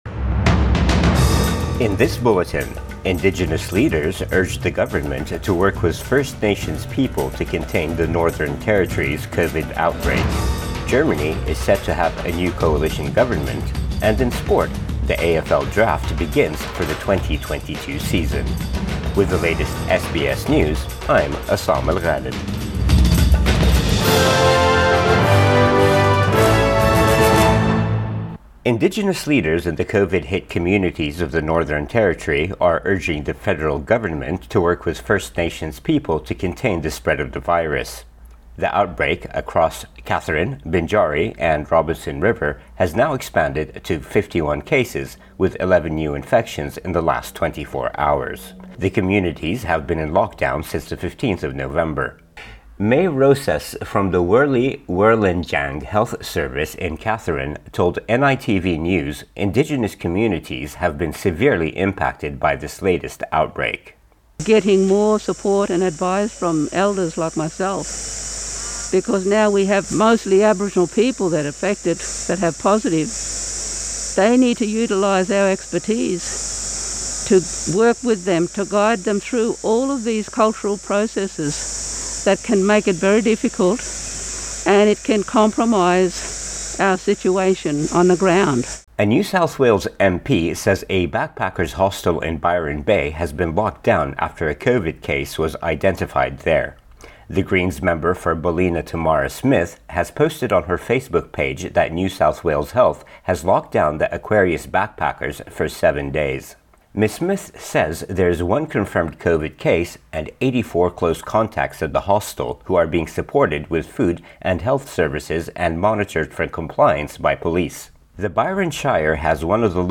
AM Bulletin 25 November 2021